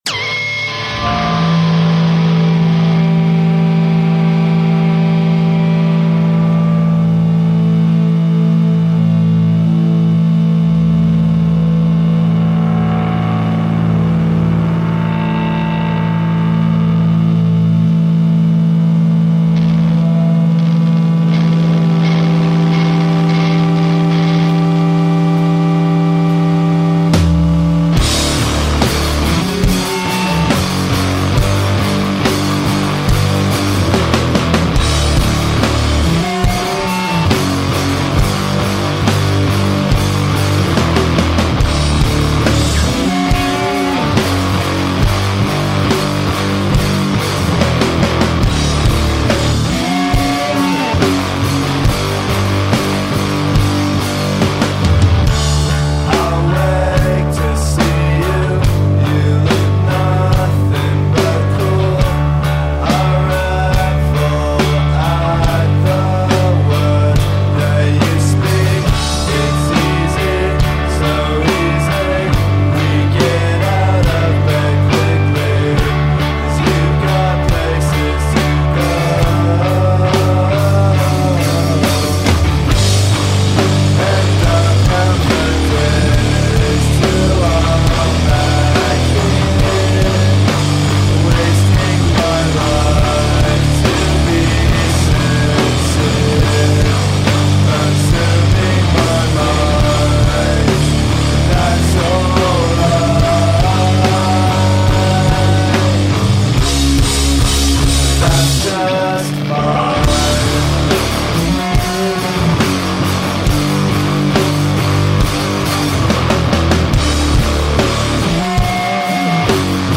Guitar/Vocals
Bass
Drums